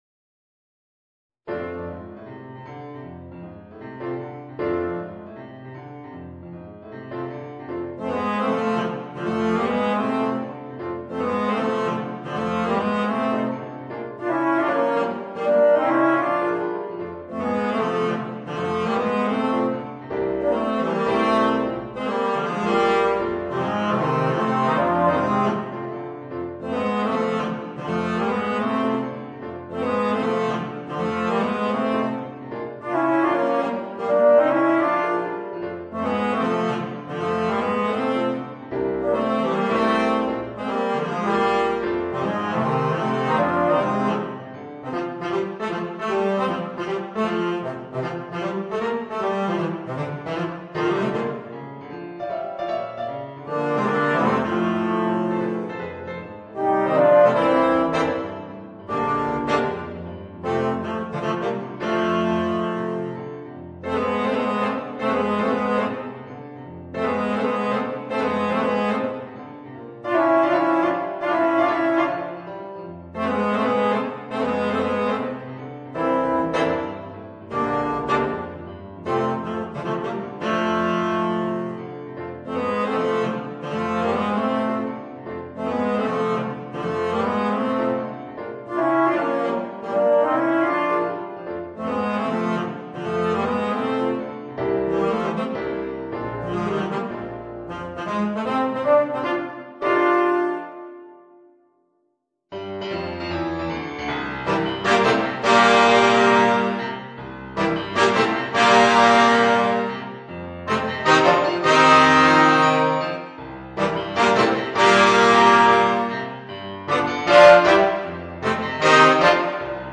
Voicing: Tenor Saxophone, Trombone w/ Audio